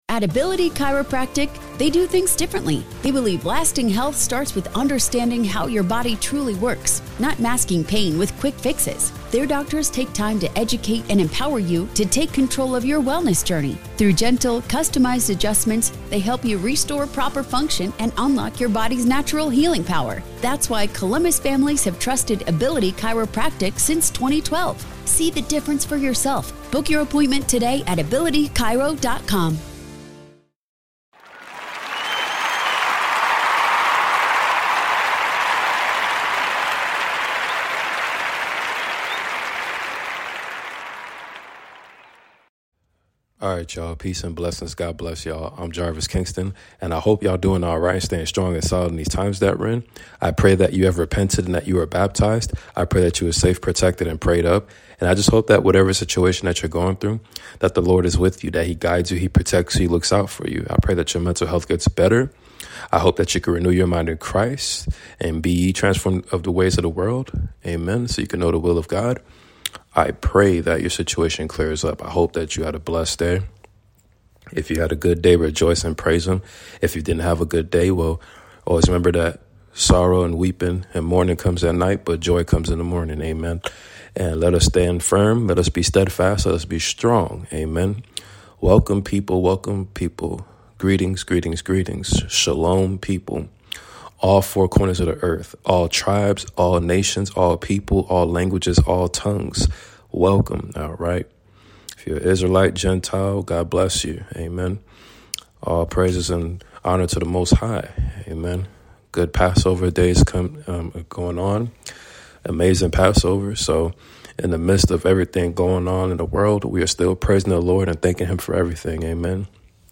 Book of Numbers reading chapters 3-7 ! Let’s stay strong and prayed up people !